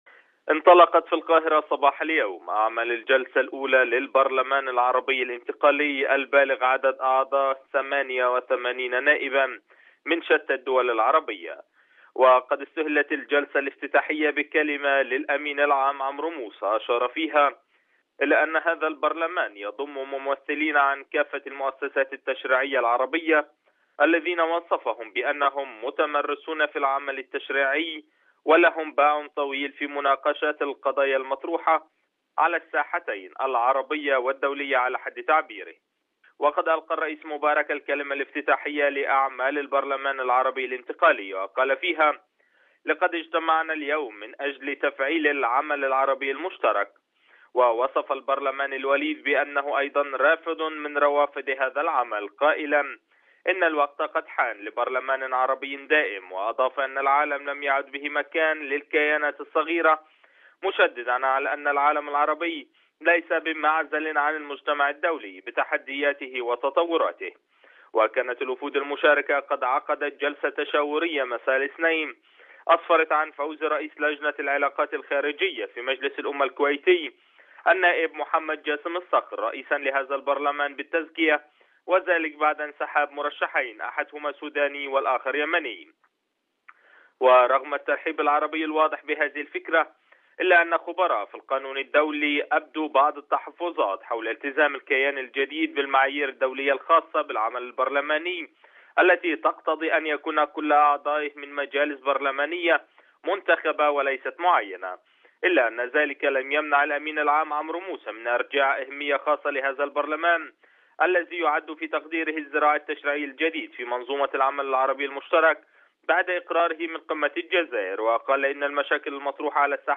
عقد البرلمان العربي الانتقالي أول اجتماعاته في مقر الجامعة العربية بالقاهرة. التفاصيل في تقرير مراسلنا من القاهرة.